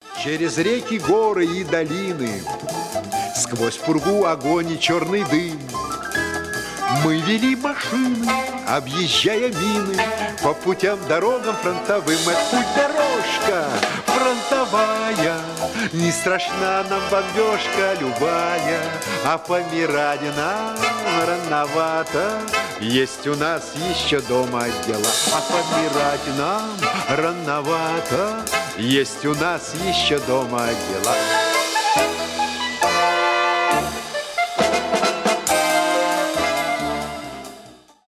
ретро